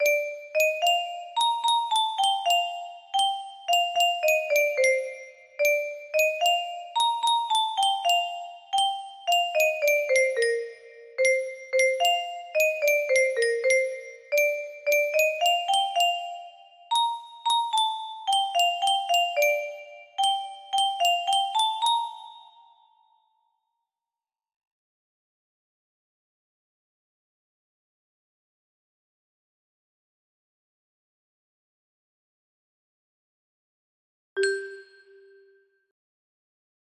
music music box melody